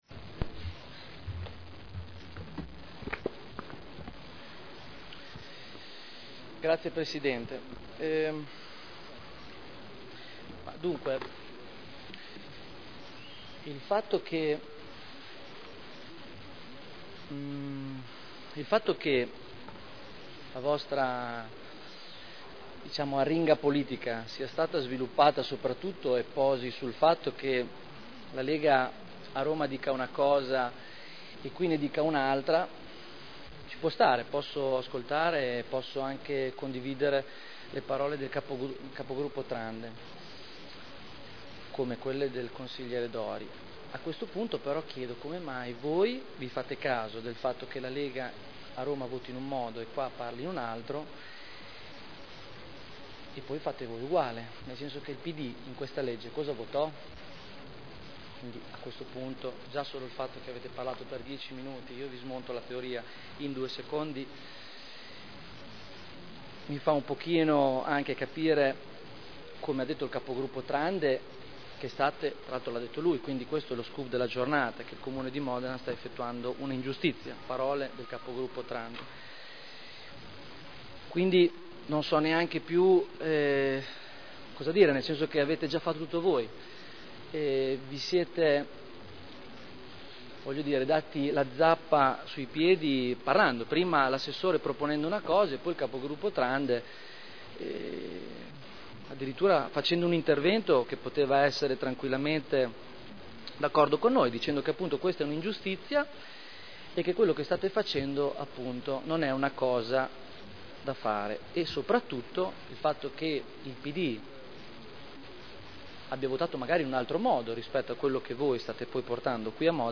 Seduta del 20/12/2010. Dibattito su delibera: Definizione agevolata dei debiti derivanti da sanzioni relative a verbali di accertamento di violazioni al codice della strada elevati dal 1.1.2000 al 31.12.2004 (art. 15 comma 8 quinquiesdecies legge 3.8.2009 n. 102) (Commissione consiliare del 14 dicembre 2010)